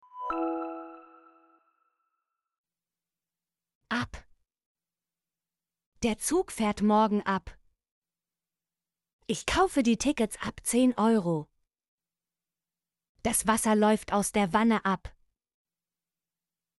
ab - Example Sentences & Pronunciation, German Frequency List